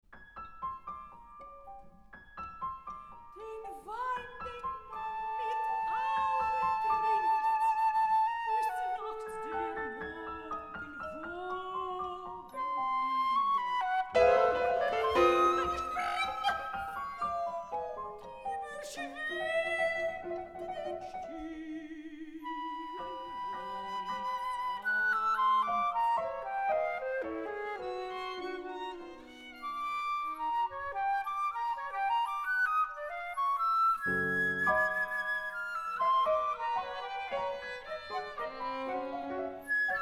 Soprano
14th International Music Festival ppIANISSIMO in Sofia - Bulgaria Some audio samples from this live performance